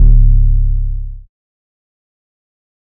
808 (OZ).wav